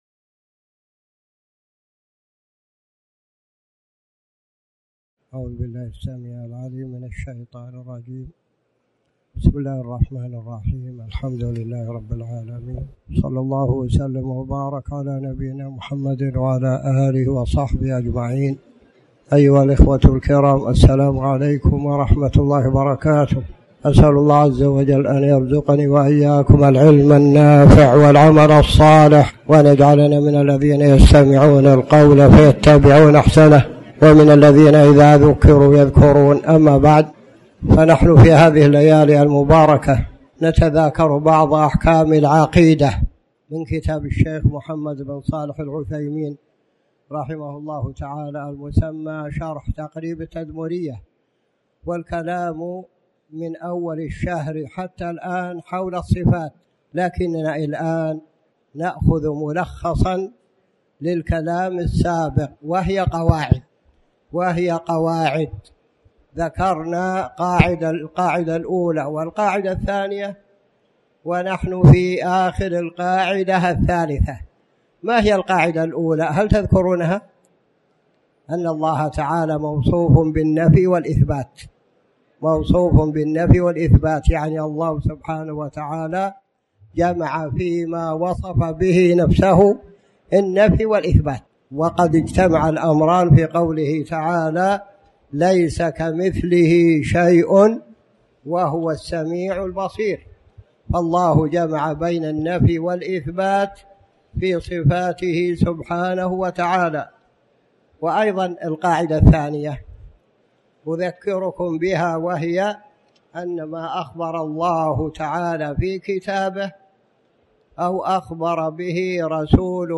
تاريخ النشر ٢٥ ذو القعدة ١٤٣٩ هـ المكان: المسجد الحرام الشيخ